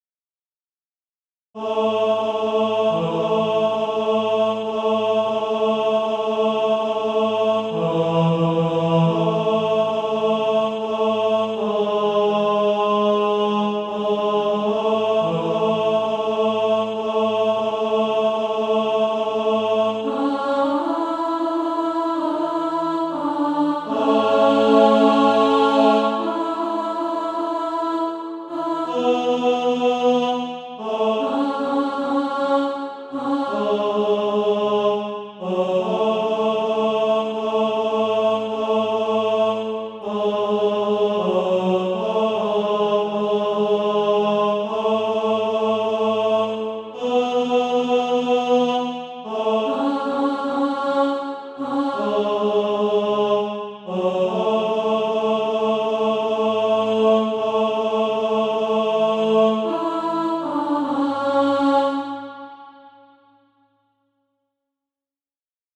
Tenor Track.
Practice then with the Chord quietly in the background.